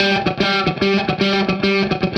Index of /musicradar/80s-heat-samples/110bpm
AM_HeroGuitar_110-G01.wav